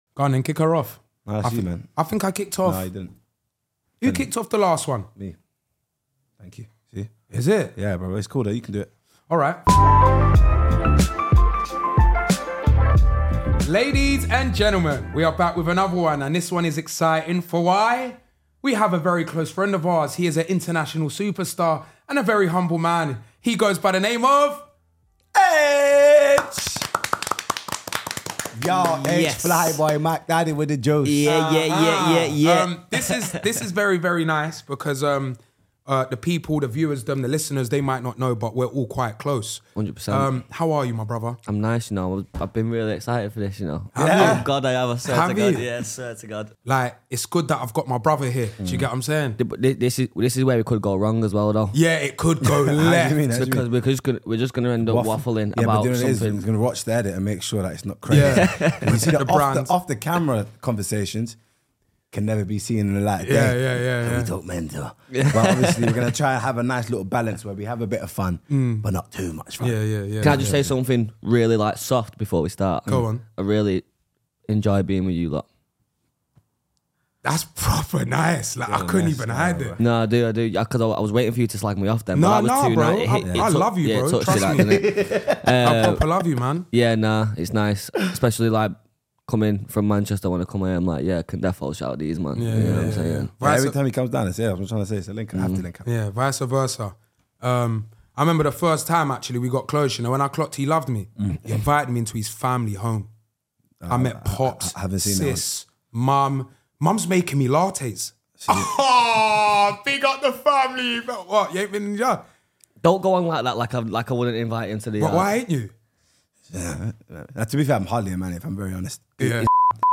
Aitch, one of the biggest stars in the UK music scene, sits down with Chunkz and Filly to chat to real friends about real life.